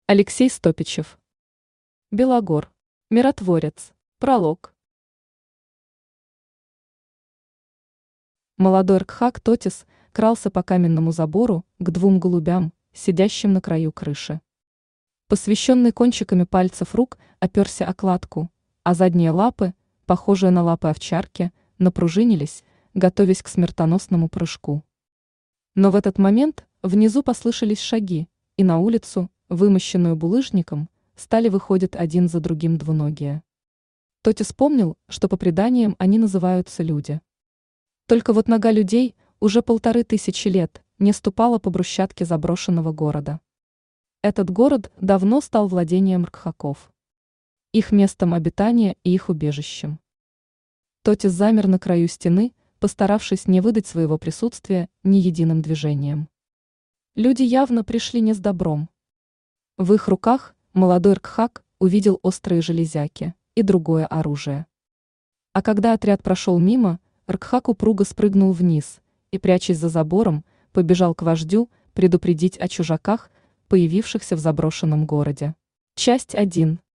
Аудиокнига Белогор. Миротворец | Библиотека аудиокниг
Миротворец Автор Алексей Александрович Стопичев Читает аудиокнигу Авточтец ЛитРес.